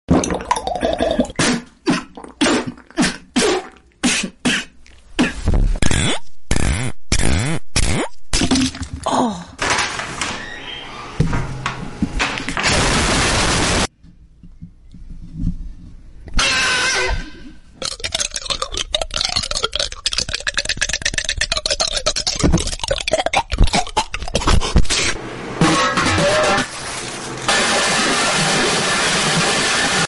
Ranking The Most Insane ASMR